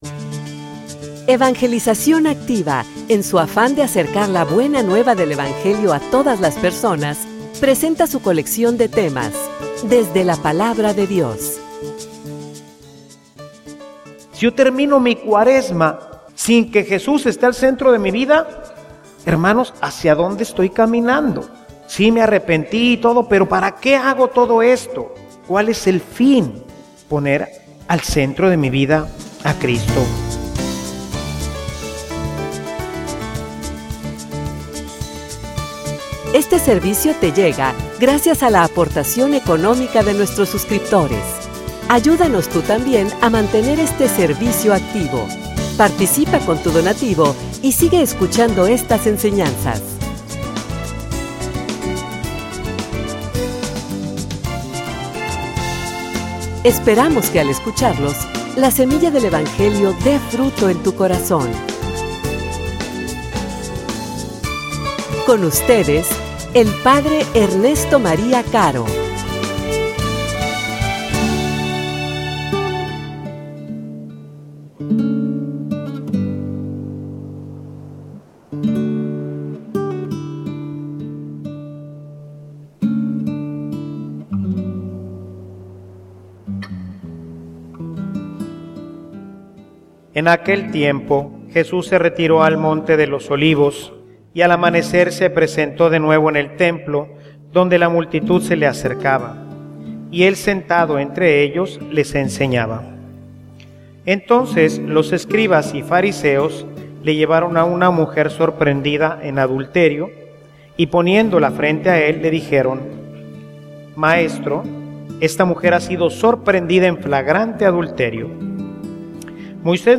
homilia_Centralidad_en_Cristo.mp3